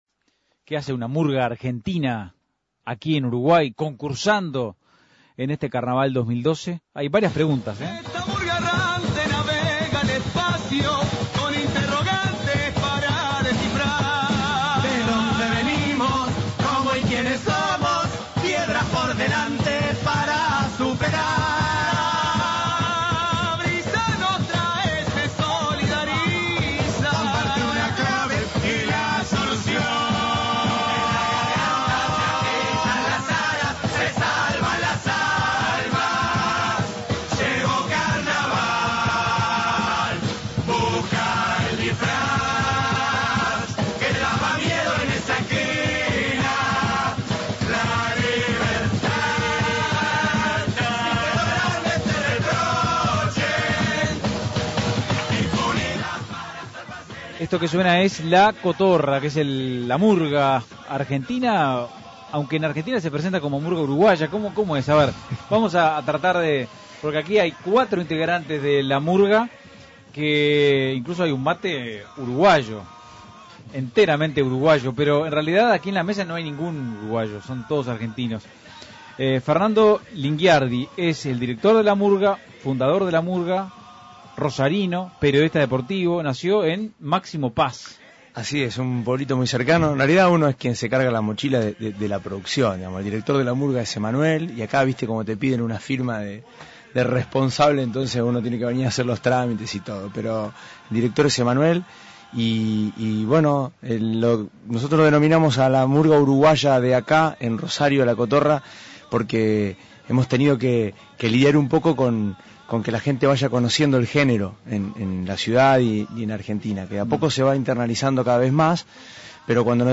Entrevista a los intgrantes de La Cotorra.